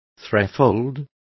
Complete with pronunciation of the translation of threefold.